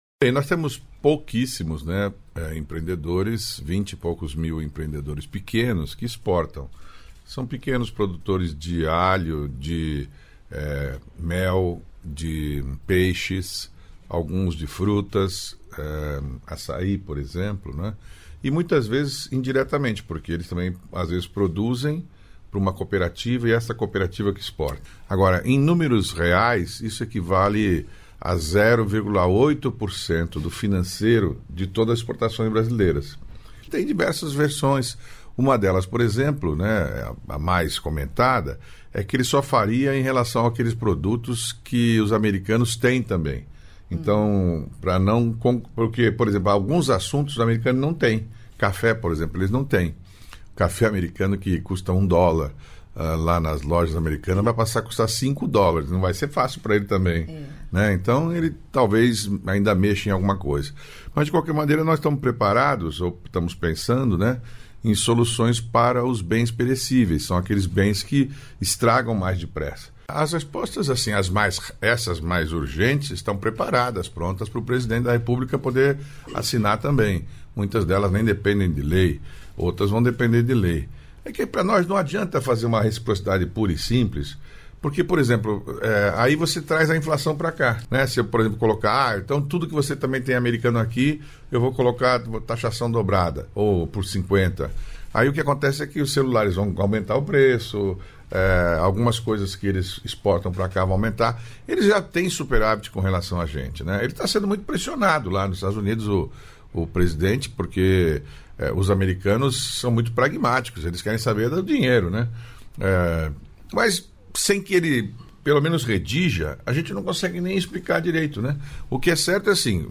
Trecho da participação do ministro do Empreendedorismo, da Microempresa e da Empresa de Pequeno Porte, Márcio França, no programa "Bom Dia, Ministro" desta quarta-feira (30), nos estúdios da EBC em Brasília (DF).